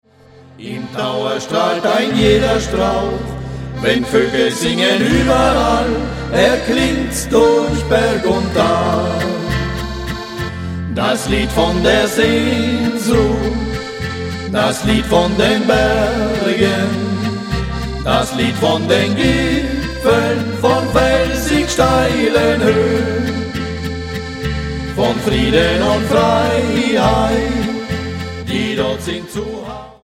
Genre: Volkstümliche Musik
Akkordeon, Gesang
Gitarre, Gesang
Kontrabass, Gesang